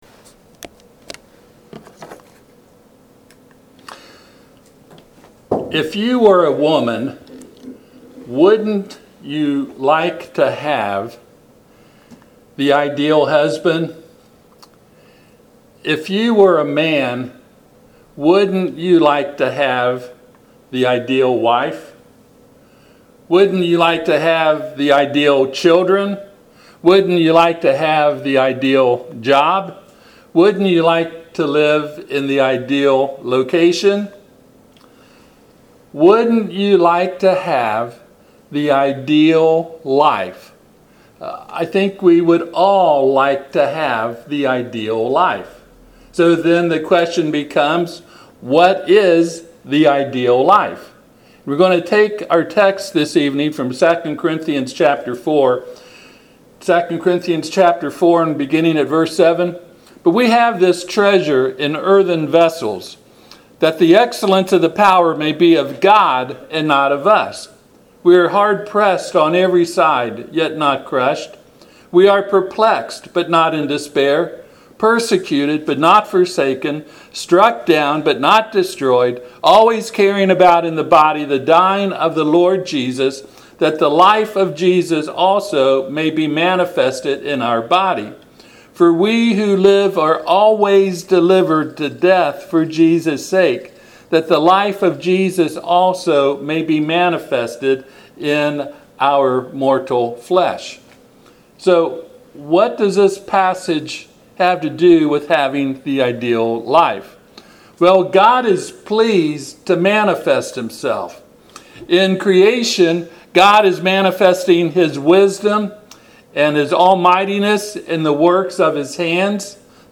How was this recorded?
Passage: 2 Corinthians 4:7-11 Service Type: Sunday PM